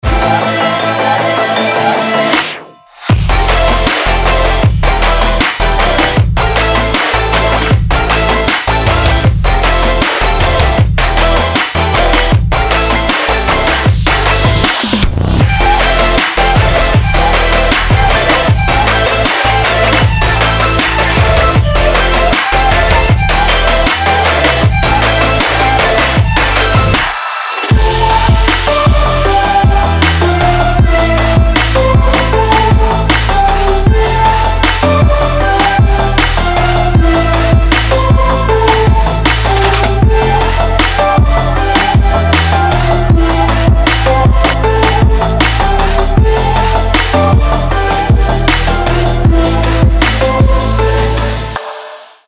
dubstep